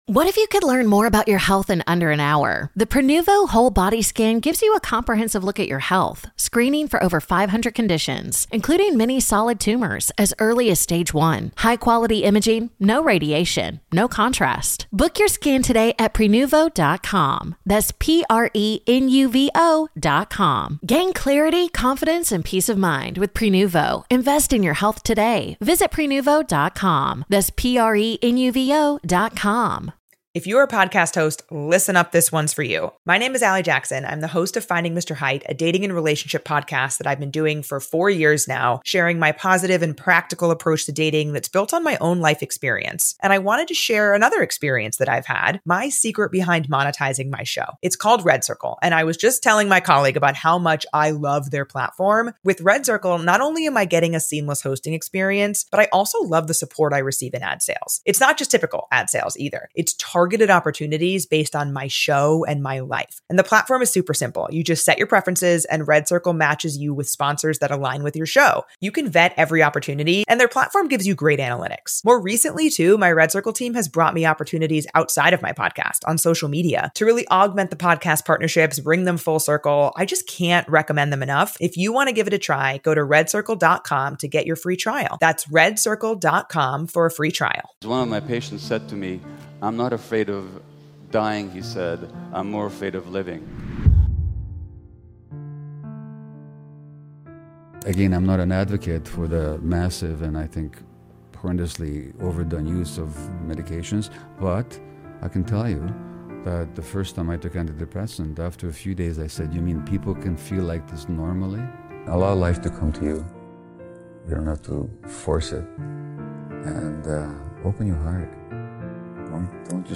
Gabor Mate - Embracing your worthiness motivational speech